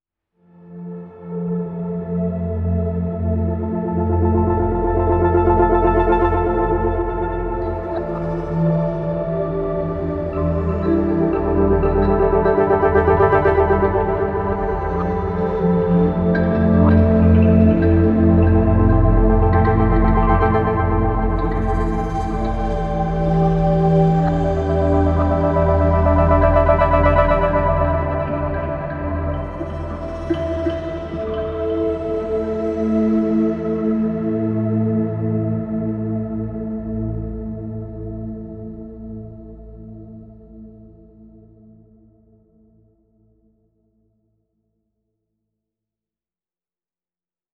• Жанр: Электро